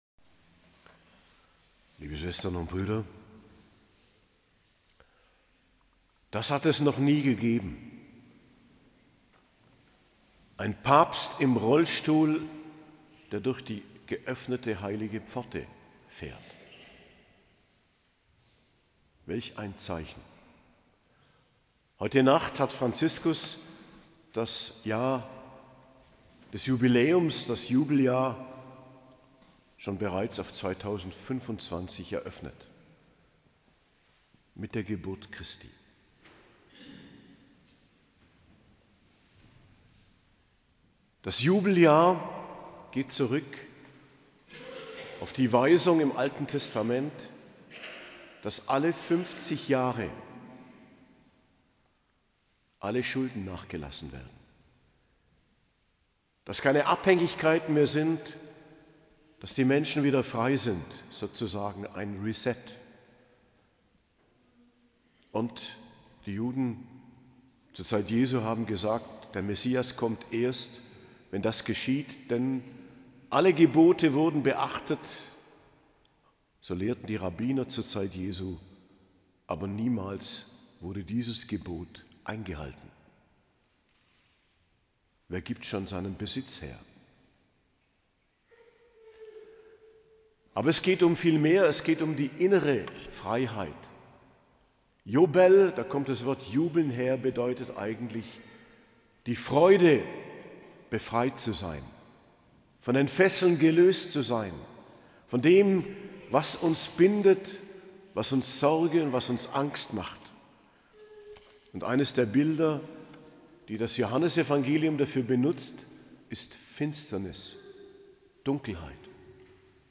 Predigt zu Weihnachten - Hochfest der Geburt des Herrn, 25.12.2024 ~ Geistliches Zentrum Kloster Heiligkreuztal Podcast